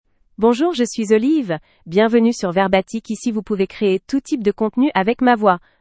OliveFemale French AI voice
Olive is a female AI voice for French (France).
Voice: OliveGender: FemaleLanguage: French (France)ID: olive-fr-fr
Voice sample
Listen to Olive's female French voice.